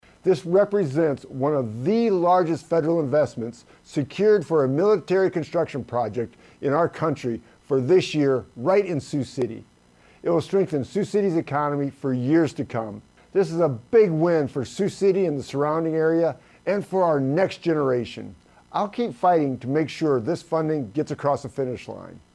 FEENSTRA SAYS HE WILL KEEP PUSHING TO MAKE SURE THE FUNDING IS PASSED BY CONGRESS: